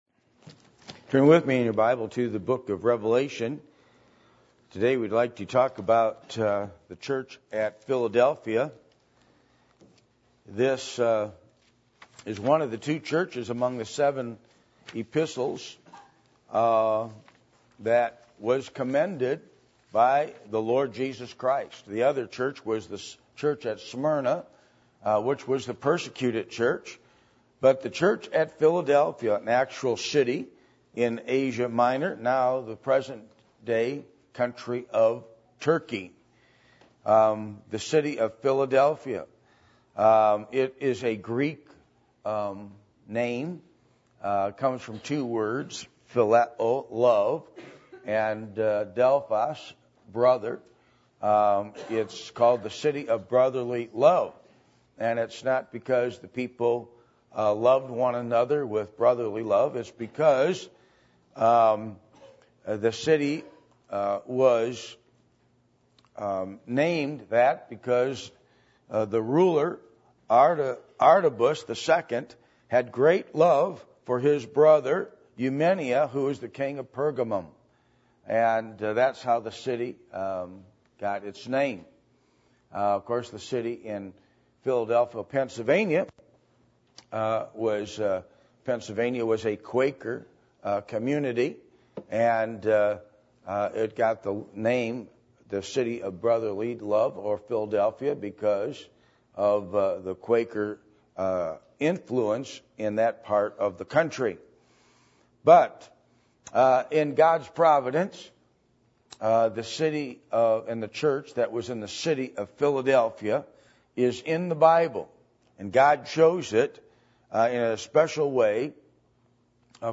Passage: Revelation 3:7-13 Service Type: Sunday Morning %todo_render% « How Do You Approach the Bible?